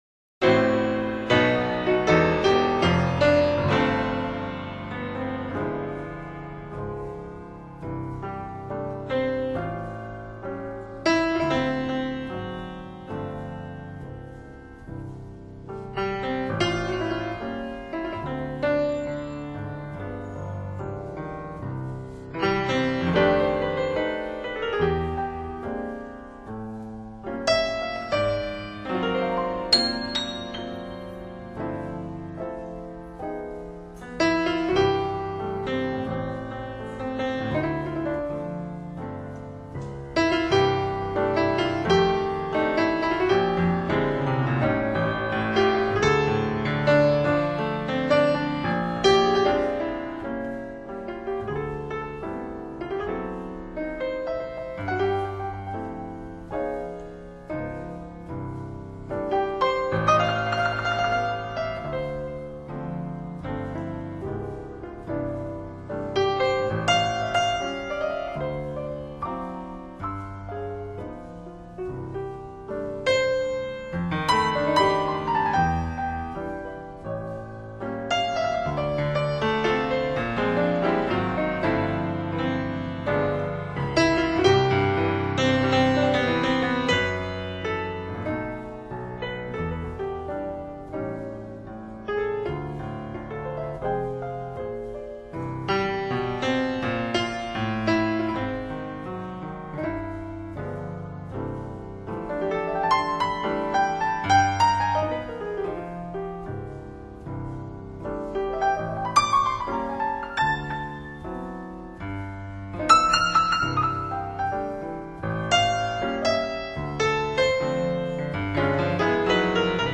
钢琴爵士音乐
斯坦威钢琴以爵士手法演奏13首经典金曲 唱片起码有三个卖点：一是录音真实。